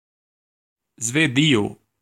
Ääntäminen
Ääntäminen UK US UK : IPA : /ˈswi.dən/ US : IPA : /ˈswi.dən/ Tuntematon aksentti: IPA : /ˈswi:.dən/ Lyhenteet ja supistumat (laki) Swed.